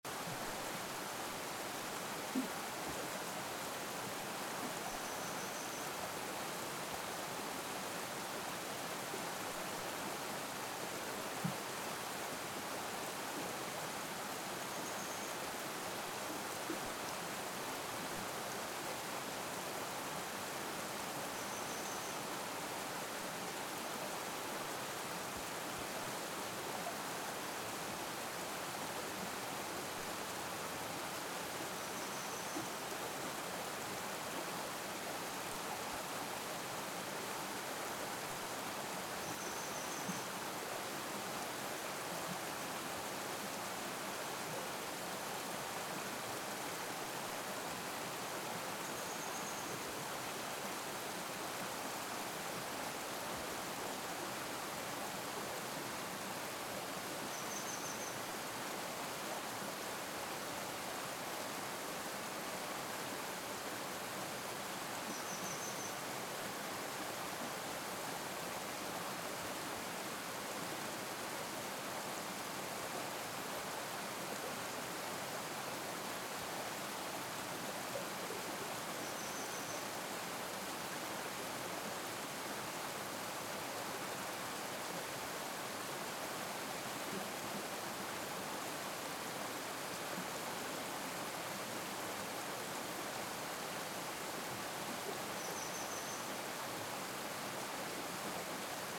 【自然環境音シリーズ】鳥のさえずり せせらぎ
なのでタイトルにあるように川沿いの「滝道」で集音した鳥のさえずりとせせらぎをお届けいたします^0^
ノイズを柔らかくしたものがこちらになります。
少し音量が下がりましたが、キーキー耳が痛くなる音がやわらぎました。
TASCAM(タスカム) DR-07Xのステレオオーディオレコーダー使用しています。